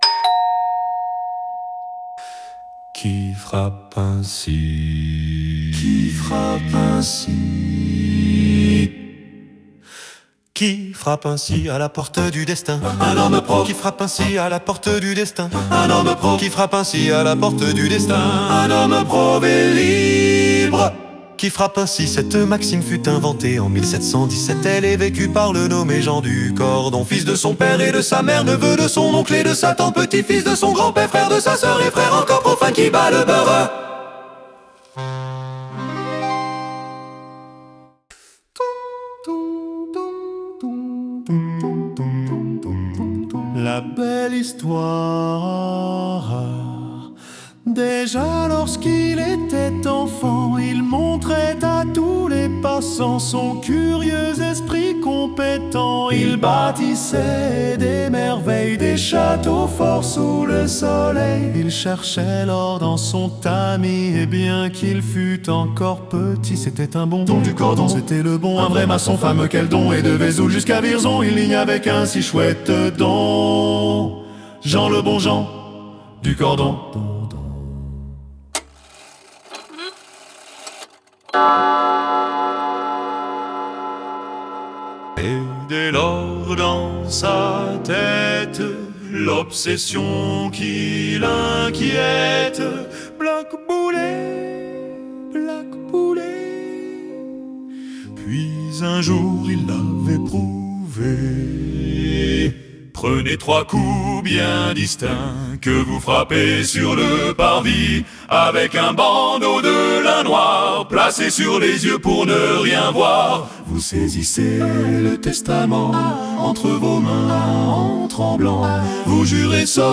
Parodie maçonnique